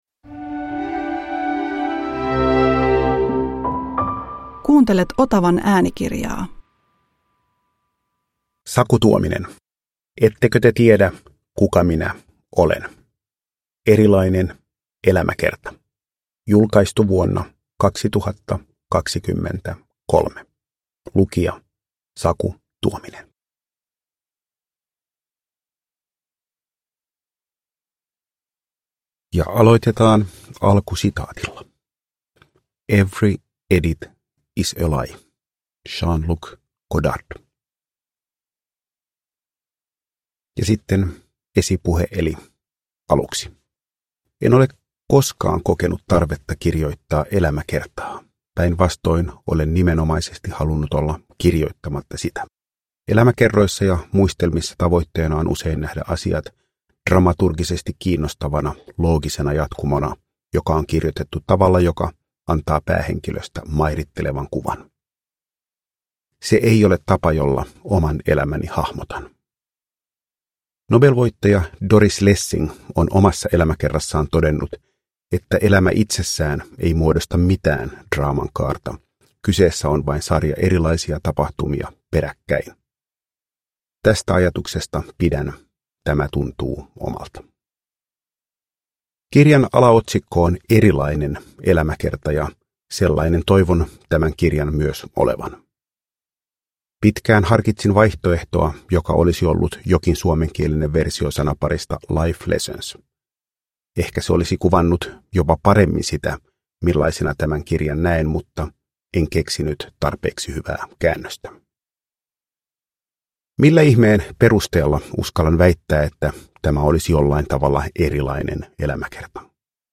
Saku Tuomisen oma ääni kannattelee kokonaisuutta, joka rakentuu lyhyistä tarinoista ja hänen mieleensä painuneista havainnoista ja lauseista.
Uppläsare: Saku Tuominen